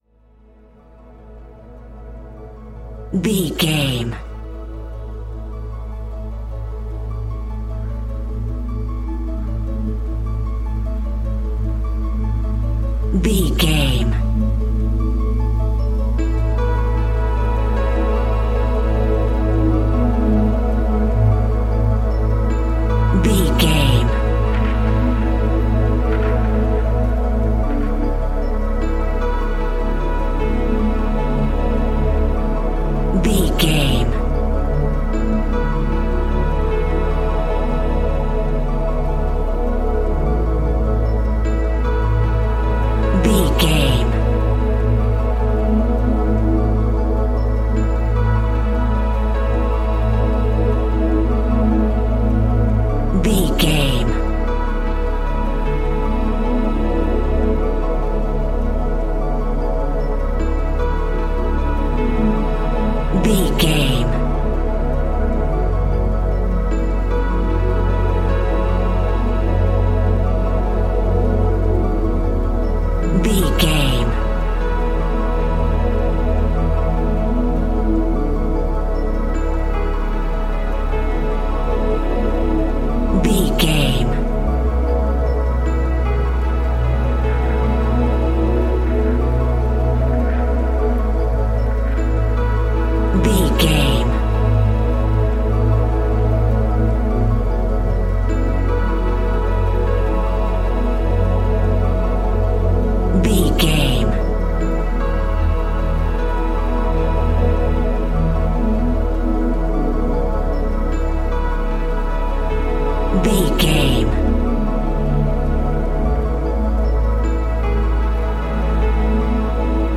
Ionian/Major
Slow
calm
ambient
ethereal
meditative
melancholic
piano
synthesiser